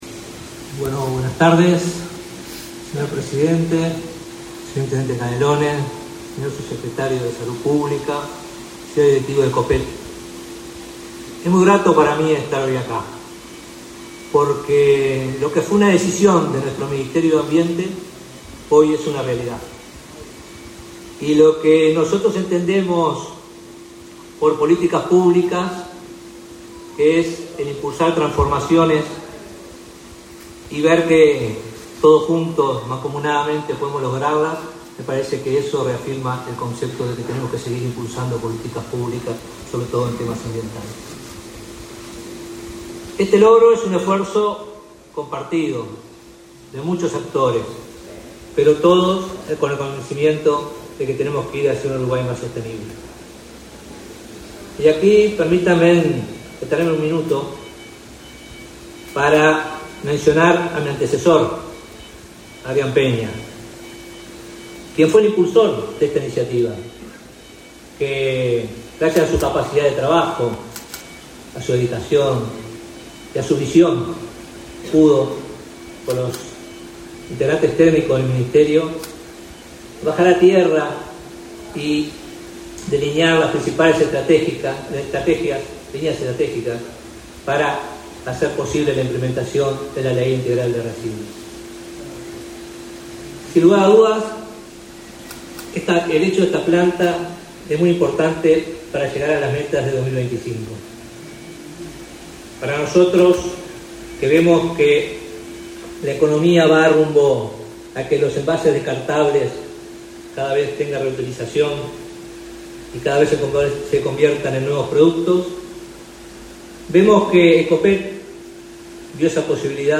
Palabras del ministro de Ambiente, Robert Bouvier
Palabras del ministro de Ambiente, Robert Bouvier 08/10/2024 Compartir Facebook X Copiar enlace WhatsApp LinkedIn Con la presencia del presidente de la República, Luis Lacalle Pou, se realizó, este 8 de octubre, el acto de inauguración de las instalaciones de la planta industrial de la empresa Envases, en Pando. En la oportunidad, disertó el ministro de Ambiente, Robert Bouvier.